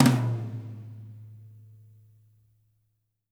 -TOM 2O   -L.wav